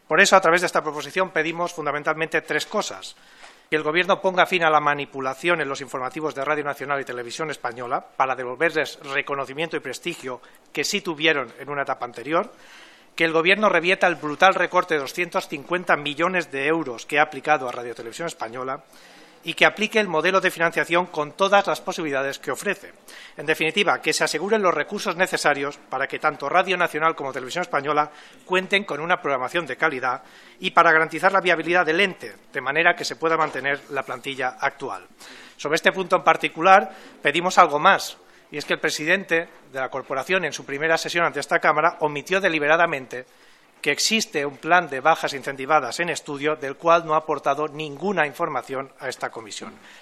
Fragmento de la intervención de Germán Rodríguez en defensa de una Proposición no de ley para devolver la calidad y la independencia a RTVE y garantizar su viabilidad (GPS)16/12/2014